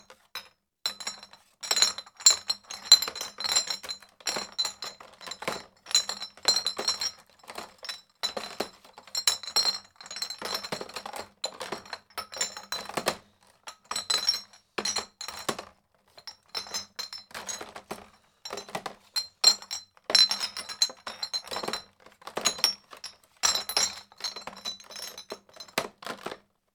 crate bottles